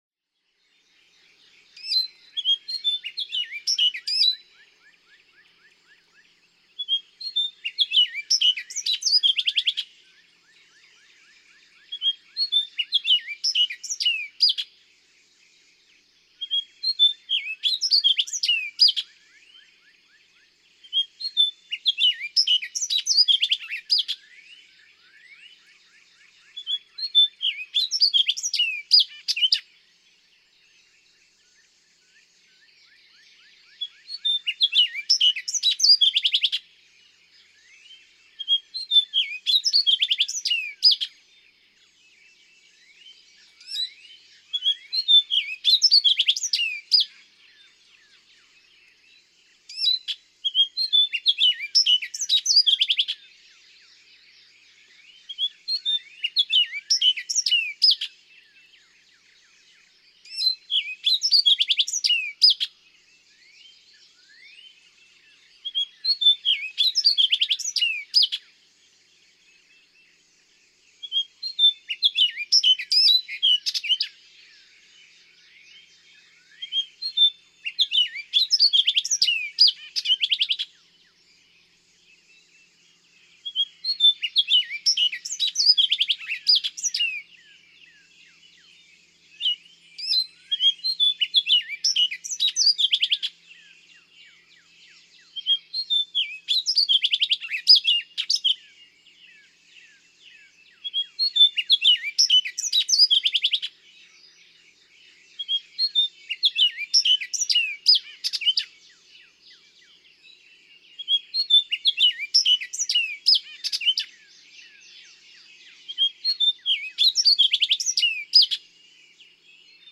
Orchard oriole
Subchapter: Energized dawn singing
At dawn, he doubles the pace, with seemingly boundless energy and enthusiasm, typically singing from a low bush.
Cave-in-Rock State Park, Elizabethtown, Illinois.
492_Orchard_Oriole.mp3